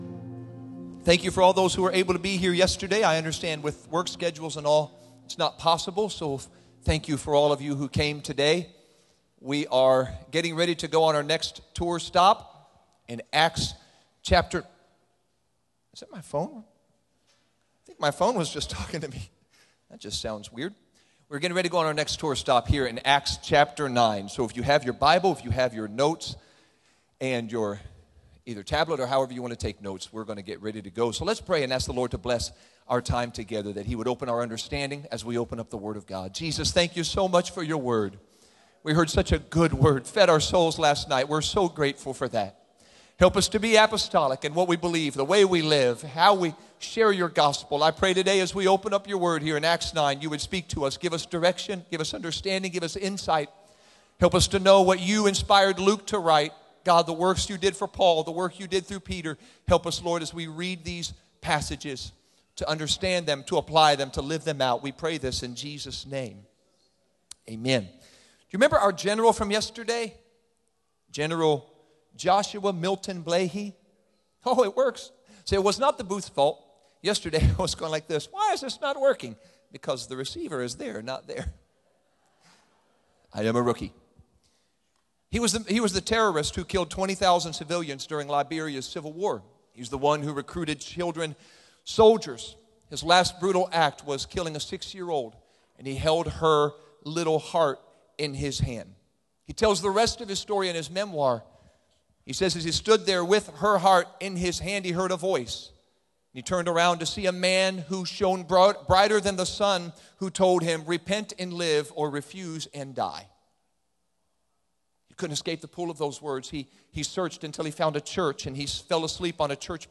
Sermon Archive | Illinois District
Camp Meeting 25 (Thursday AM)